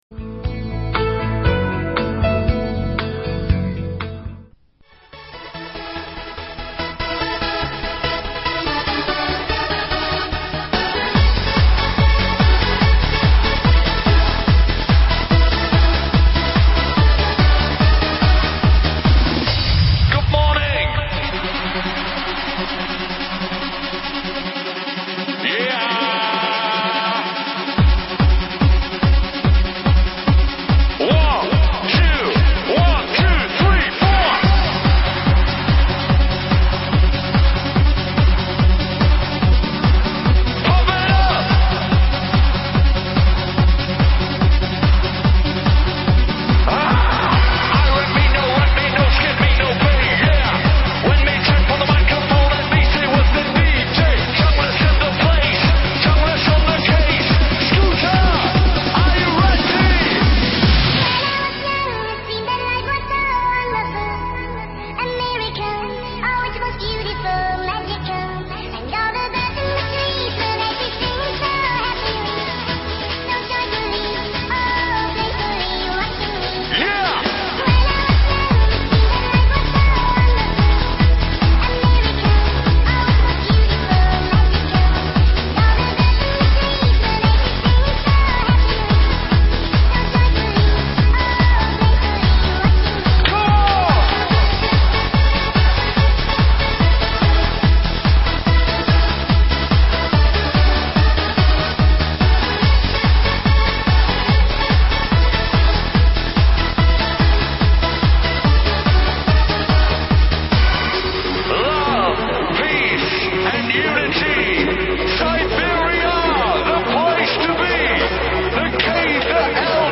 [20/9/2008]迪斯科午步